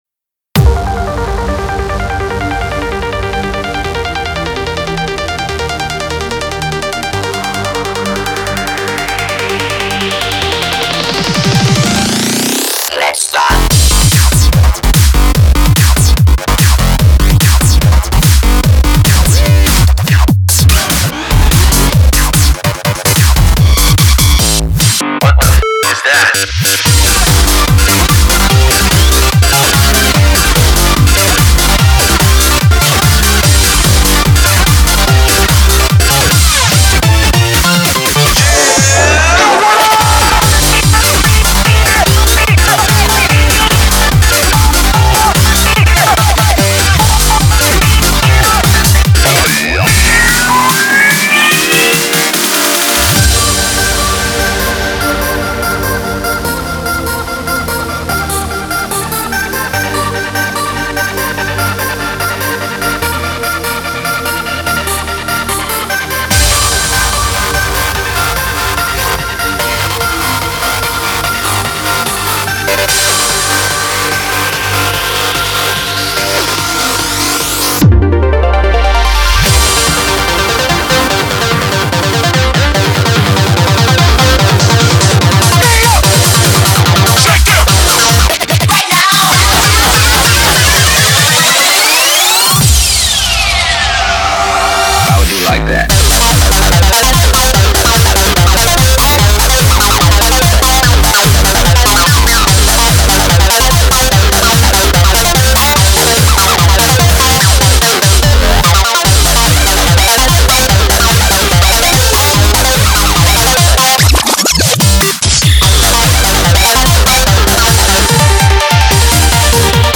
BPM146
Audio QualityPerfect (High Quality)
Comments[Hi-TECH FULL ON]